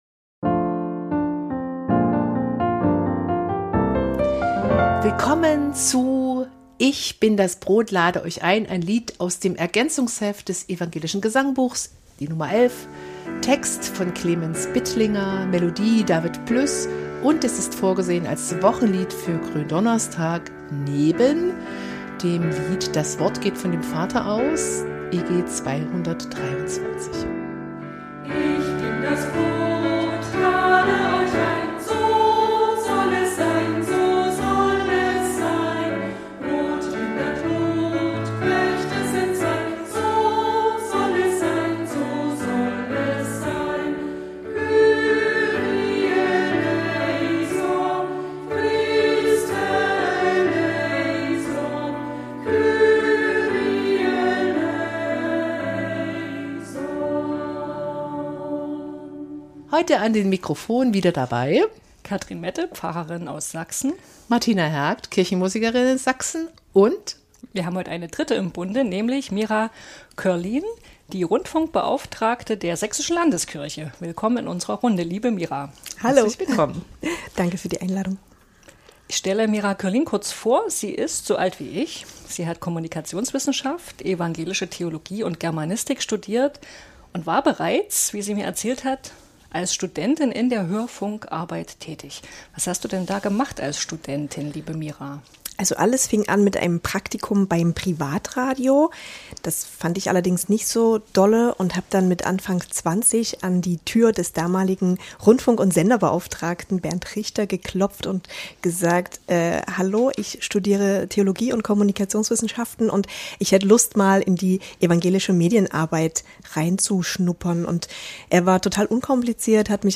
Eine Kirchenmusikerin und eine Theologin nehmen sich in jeder Folge eins der evangelischen Wochenlieder vor. Sie reden über Geschichte, Melodie und Text des Liedes und teilen Ideen, wie man es im Gottesdienst einsetzen kann.